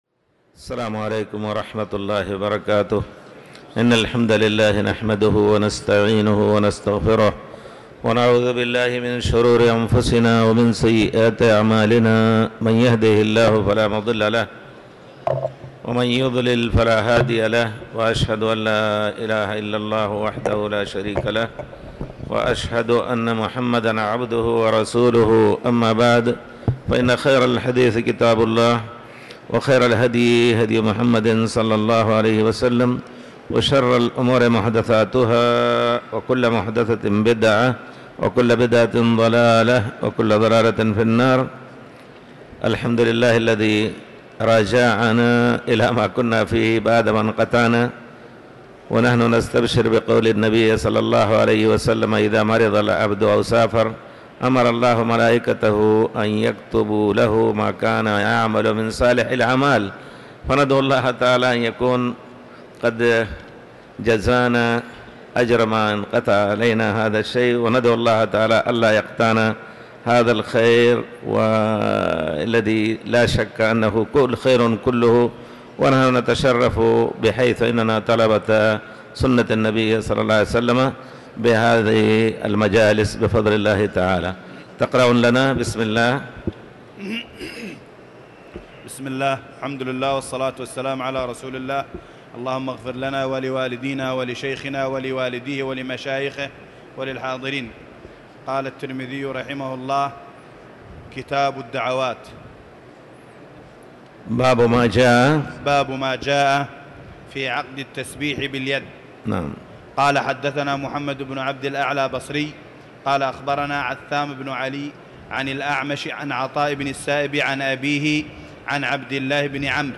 تاريخ النشر ٨ جمادى الآخرة ١٤٤٠ هـ المكان: المسجد الحرام الشيخ